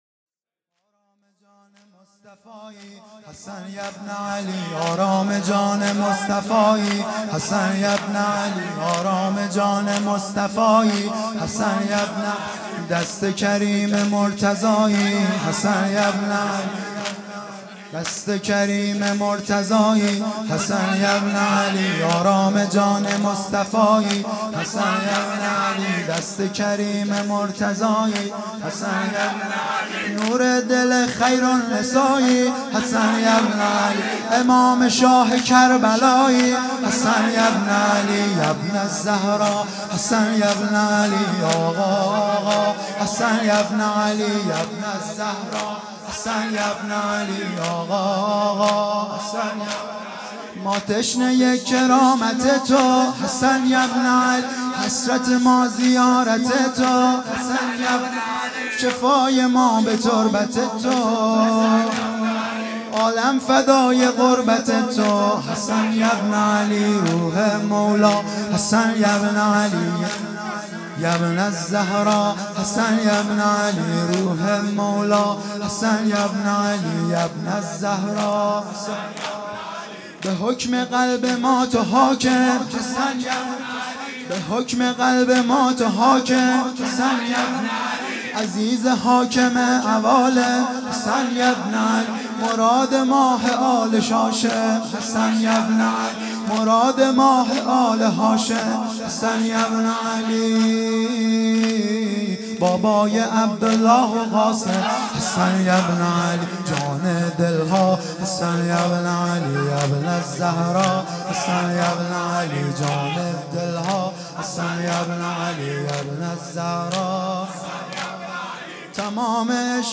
آرام جان مصطفی|شب پنجم محرم ۹۵